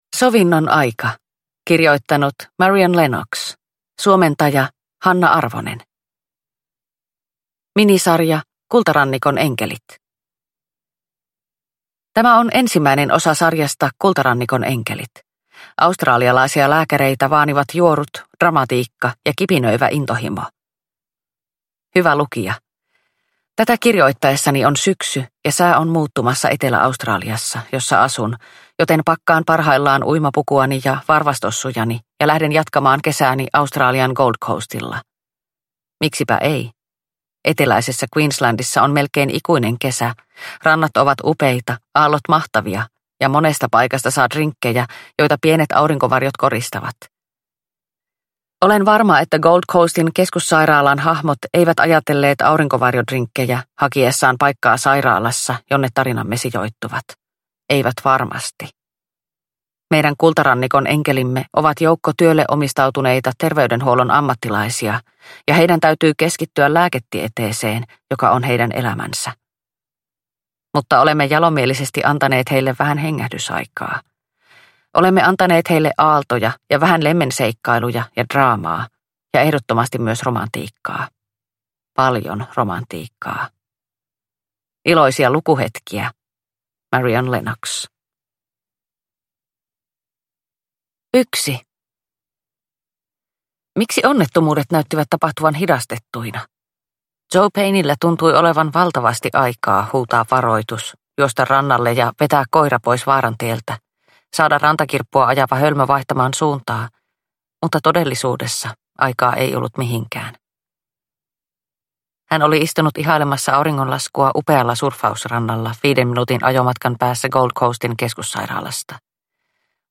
Sovinnon aika – Ljudbok – Laddas ner